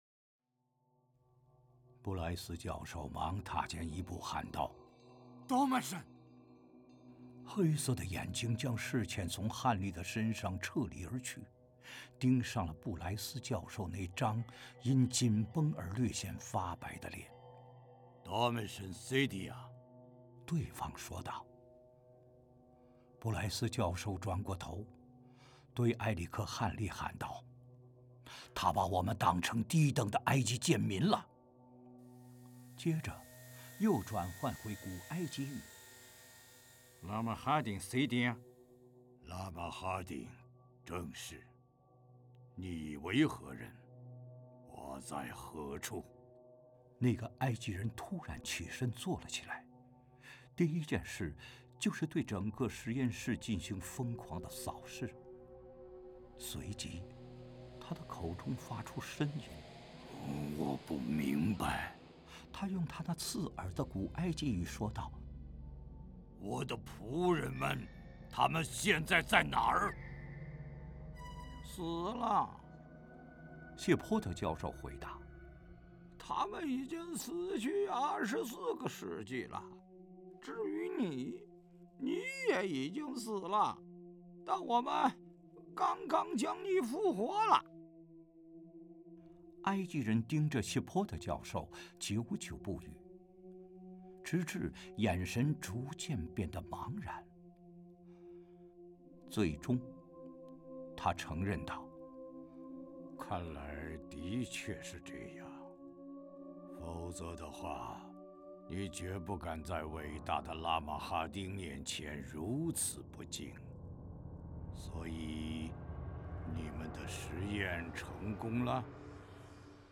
《亡灵书》有声书试听：